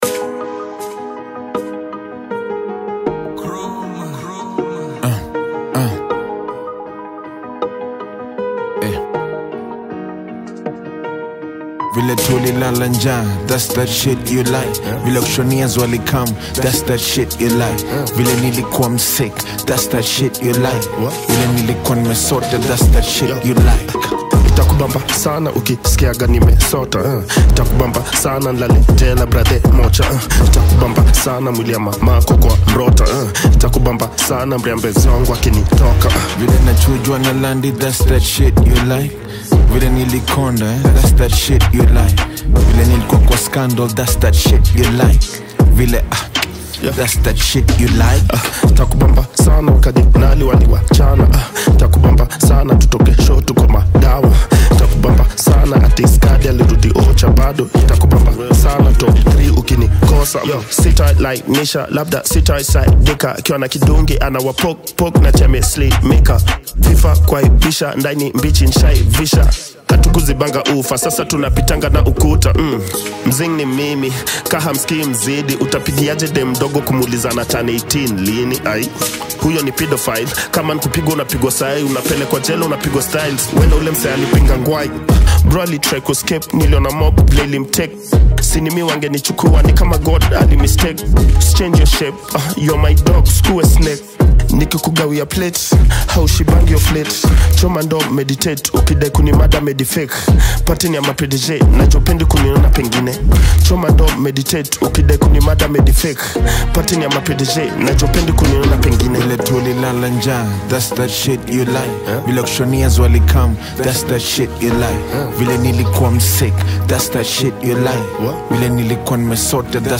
Kenyan hip-hop